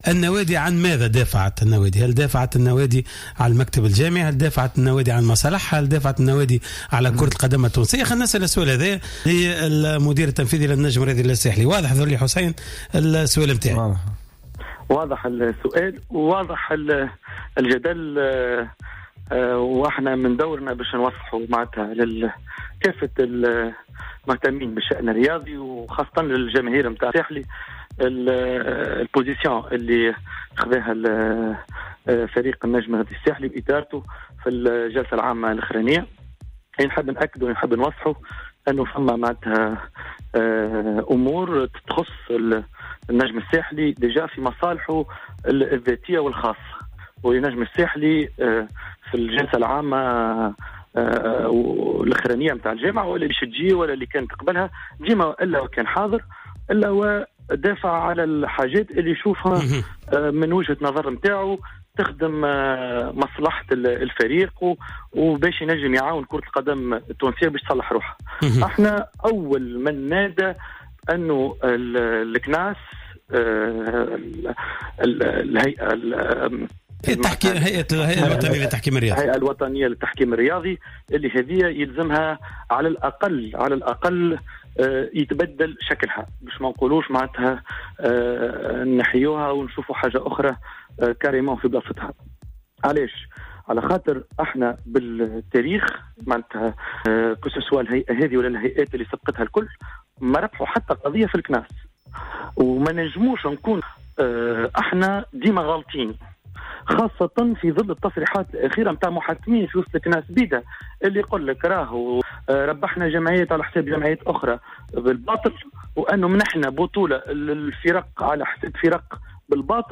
مداخلة في برنامج cartes sur table